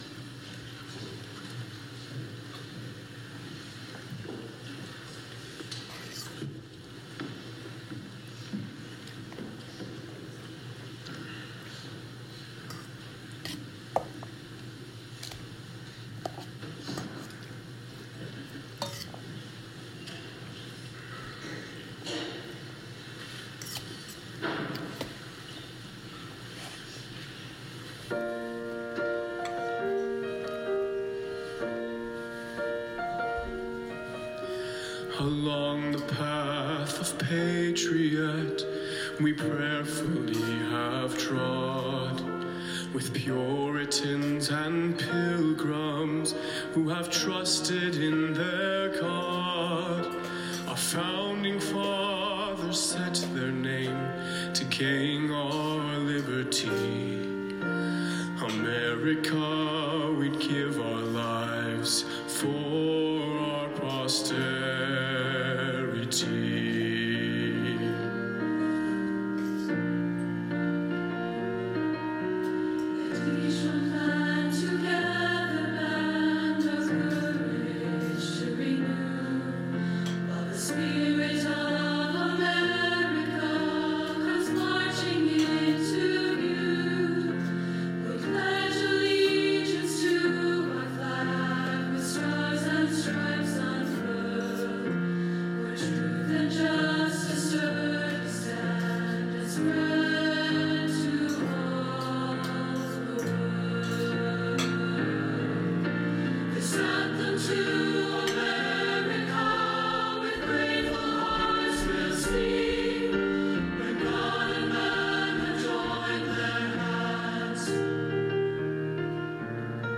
Voicing/Instrumentation: Youth Choir Mixed Or Unison
Piano